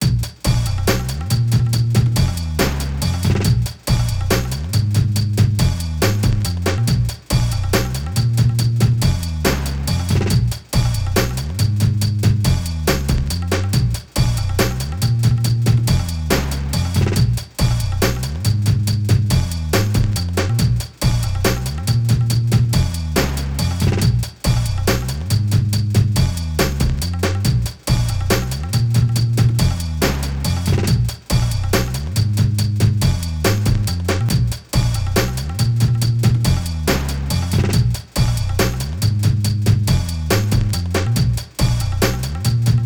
Pulso rítmico (bucle)
melodía
repetitivo
rítmico
sintetizador